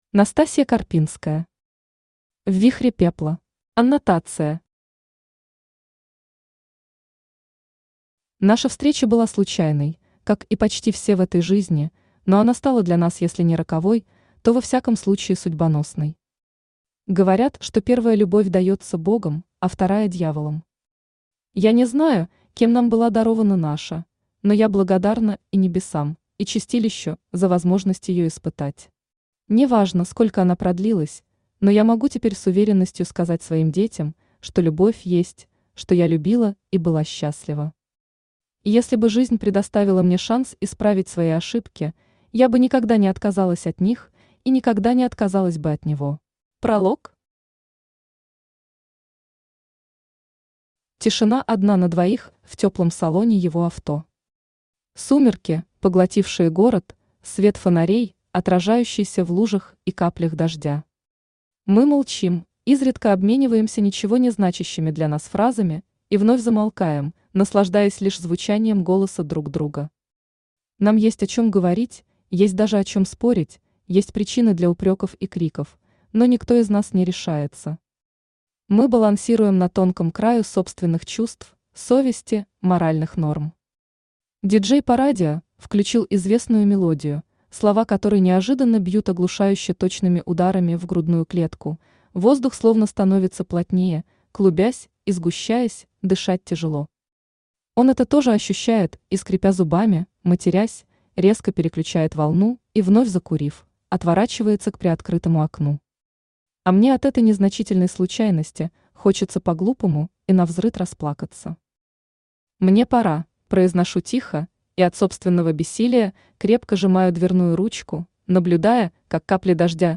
Aудиокнига В вихре пепла Автор Настасья Карпинская Читает аудиокнигу Авточтец ЛитРес.